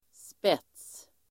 Uttal: [spet:s]